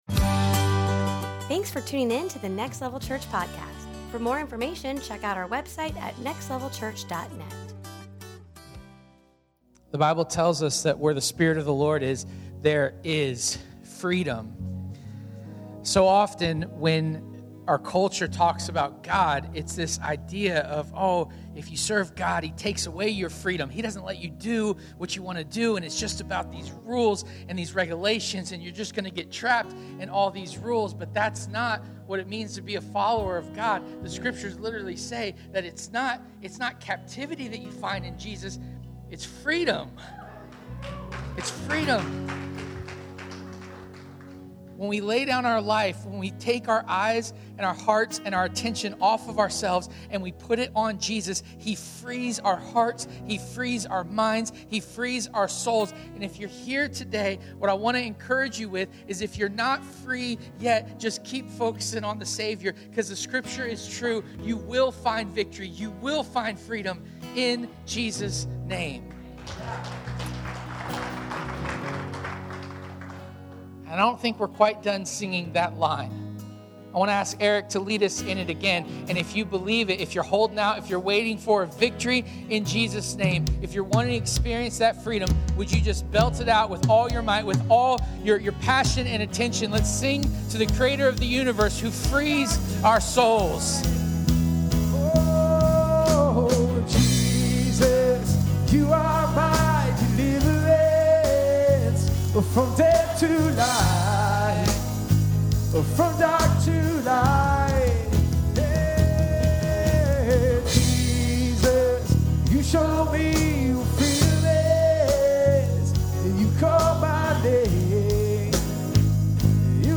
Service Type: Sunday “That’s not fair” is one of the earliest phrases we learn as humans.